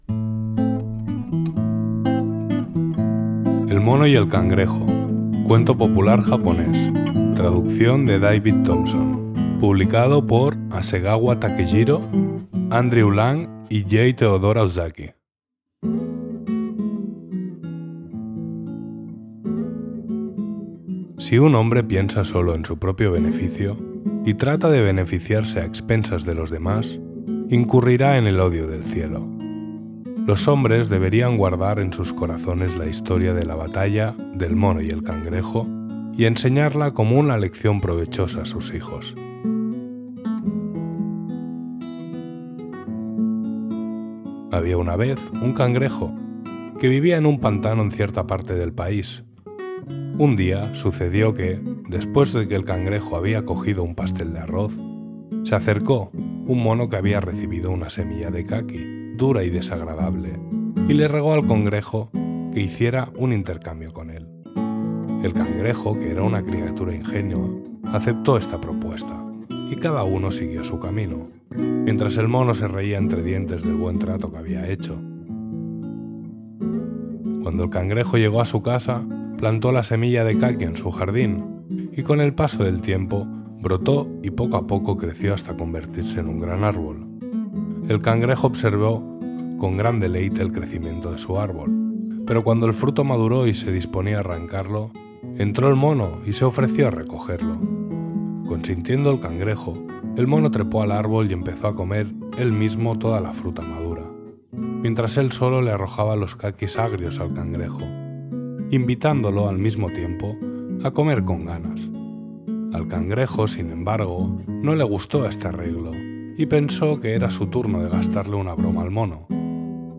Música de guitarra interpretada por